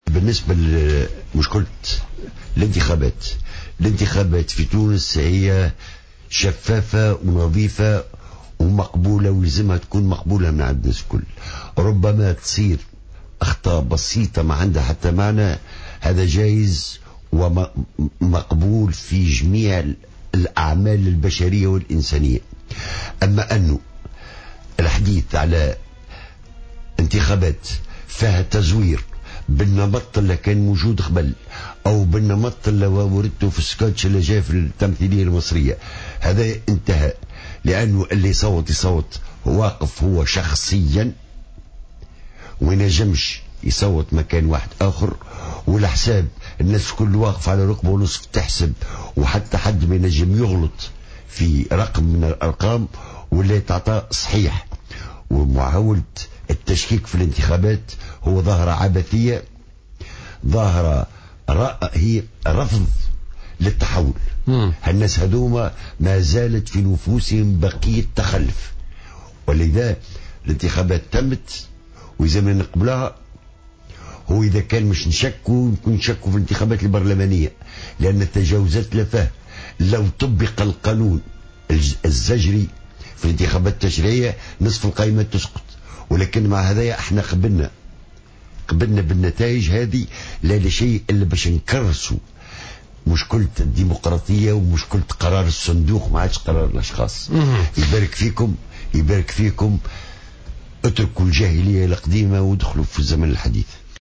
Taher Hmila, ex député de l’assemblée nationale constituante, était l’invité de Politica du mardi 23 décembre 2014.